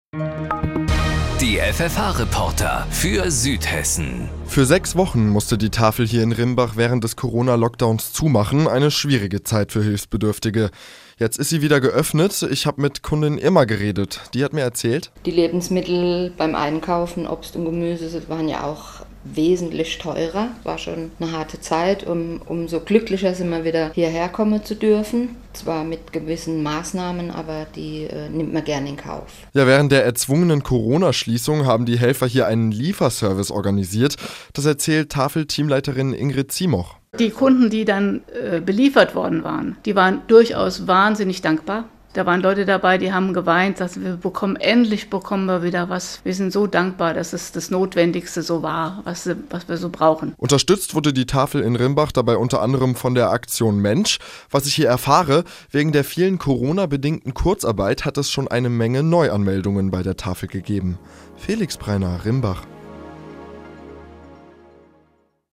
Hit Radio FFH berichtet